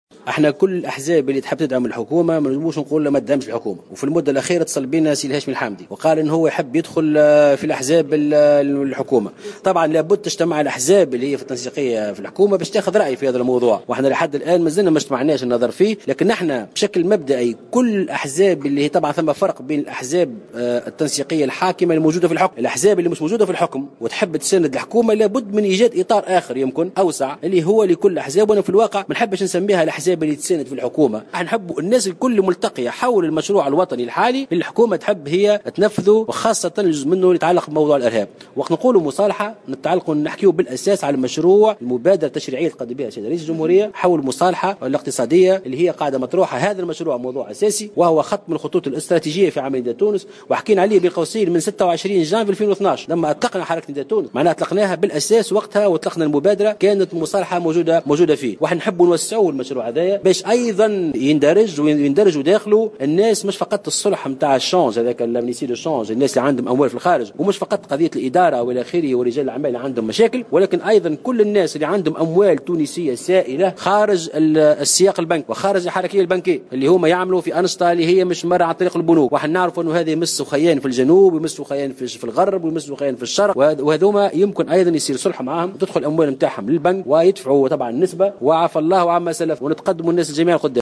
تصريح
خلال اجتماع تحضيري عقده الحزب اليوم الأحد 02 أوت 2015 بالحمامات من ولاية نابل استعدادا للمؤتمر القادم